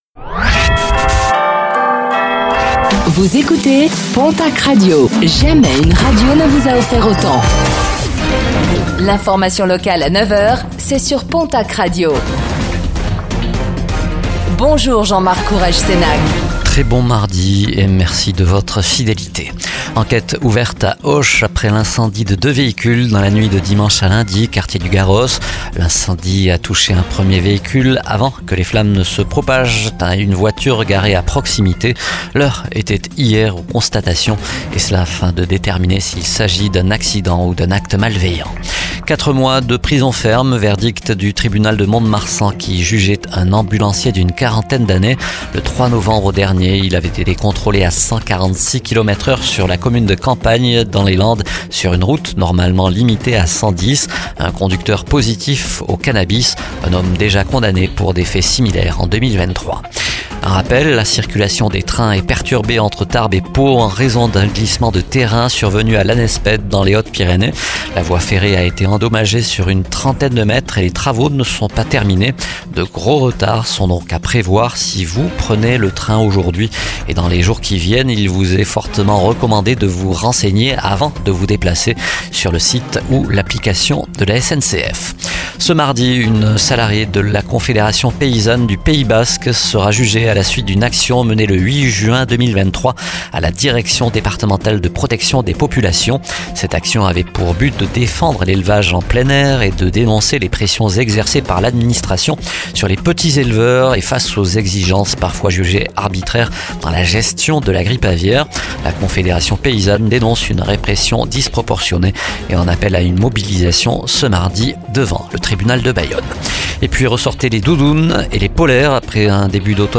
Infos | Mardi 12 novembre 2024